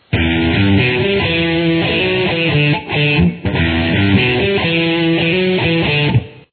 blending elements of pop, R&B, and hard rock